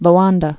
(lō-ändə)